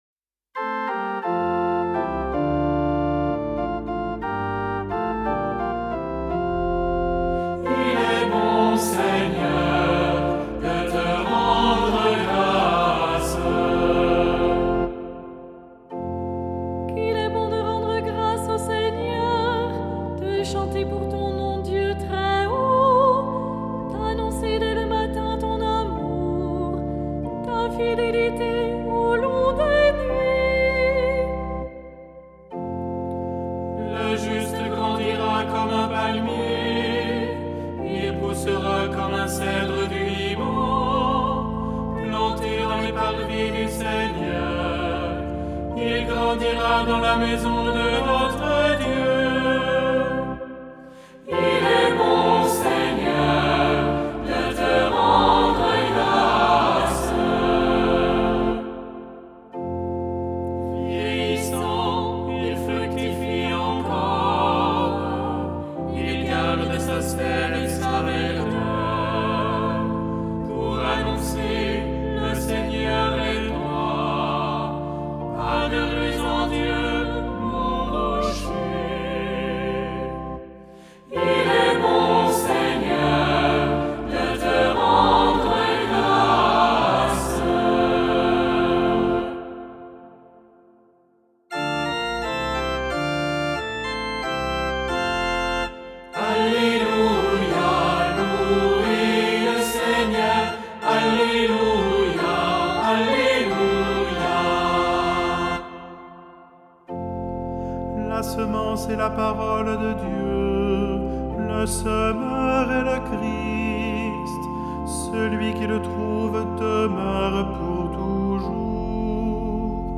Le psaume 91 se chante le jour du sabbat.
Psaume-91-Il-est-bon-Seigneur-de-te-rendre-grace-11e-dimanche-du-temps-ordinaire-annee-B.mp3